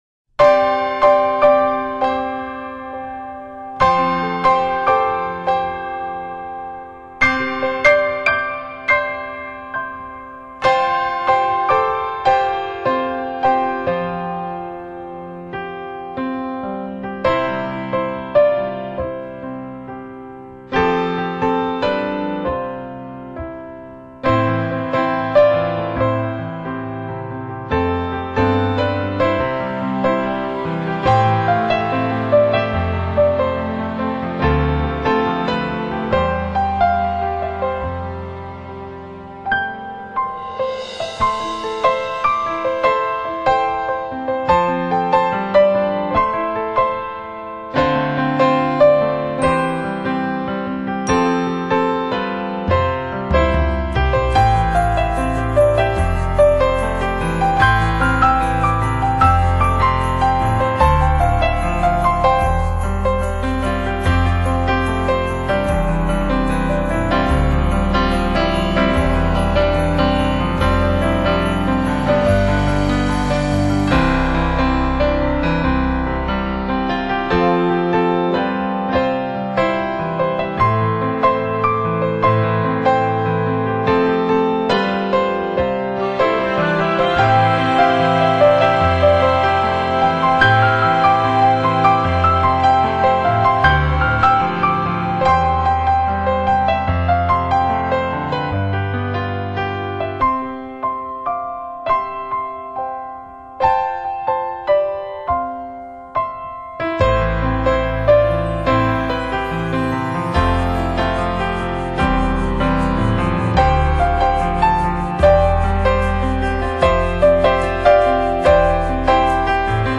音乐类型: New Age / Piano Solo